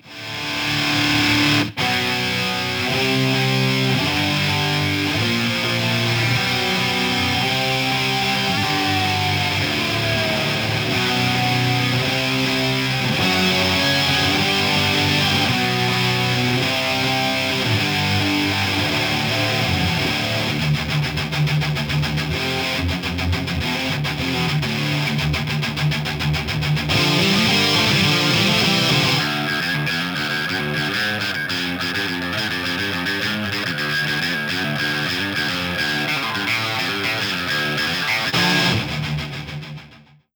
• And most relevantly to us today, GHEx will very badly resample your VGS audio.
Here's a FLAC example of its handiwork if you hate your ears. Hear that high-pitched whispering? That's called aliasing and it's nasty.
You can pretty clearly see the whispering I refer to around the 17KHz mark.